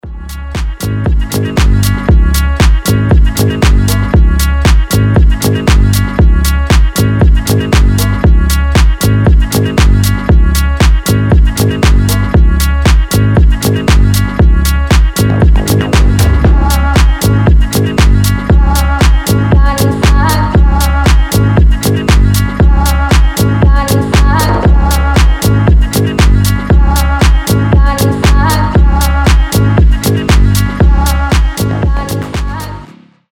гитара
мелодичные
этнические